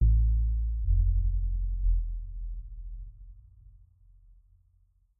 Windows X15 Shutdown.wav